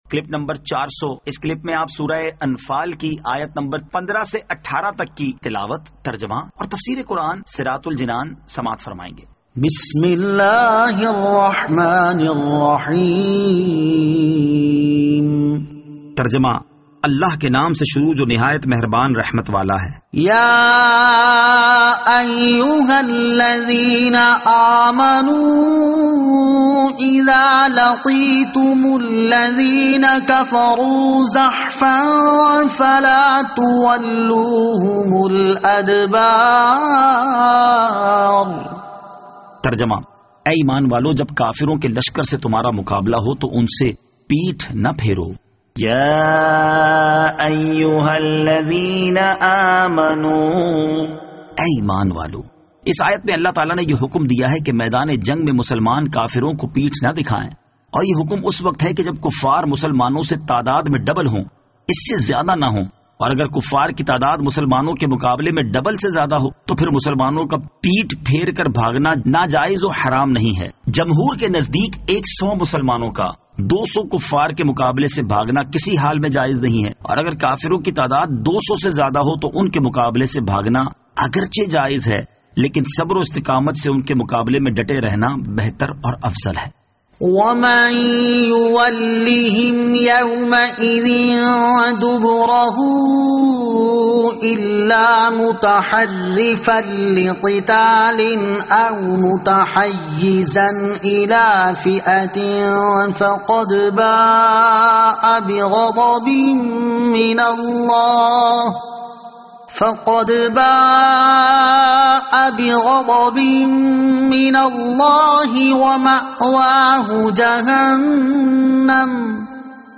Surah Al-Anfal Ayat 15 To 18 Tilawat , Tarjama , Tafseer